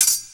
SFX_Espada2.wav